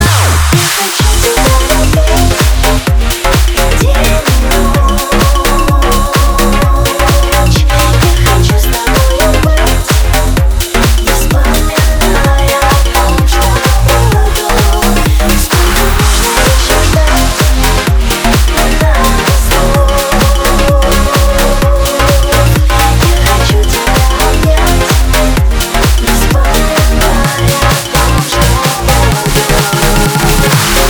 • Качество: 320, Stereo
громкие
женский вокал
dance
club
клубная музыка